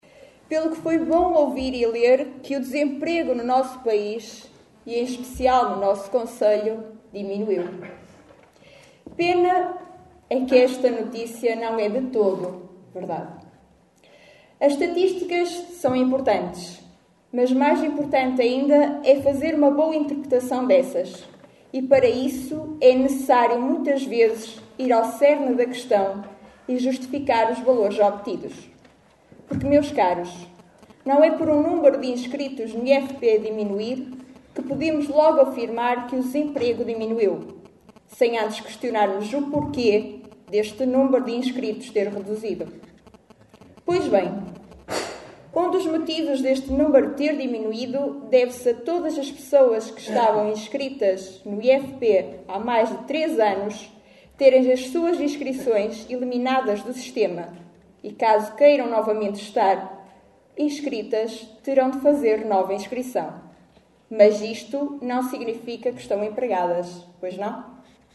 Na última reunião da Assembleia Municipal, que se realizou na passada sexta-feira dia  26 de janeiro, a deputada social democrata Simaura Fonseca, lembrou que nem todas as notícias correspondem  à verdade.
Extratos da última Assembleia Municipal de Caminha.